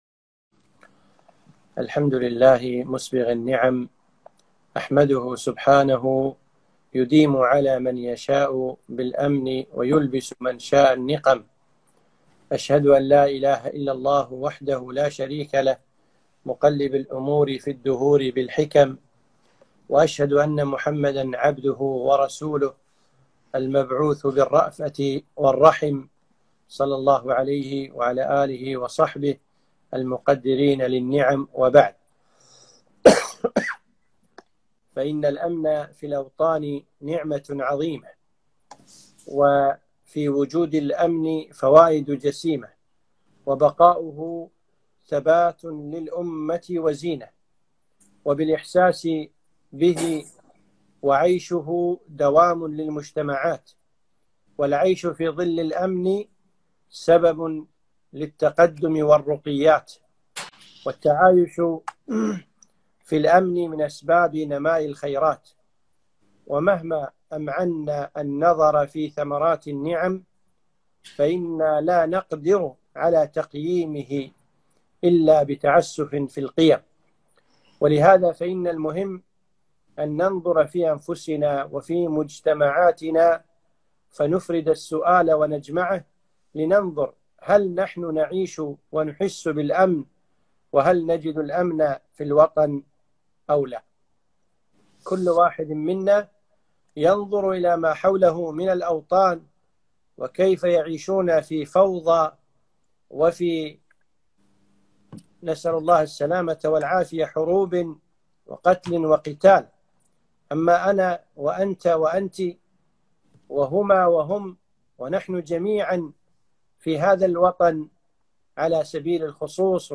محاضرة - مشاهد الأمن في الوطن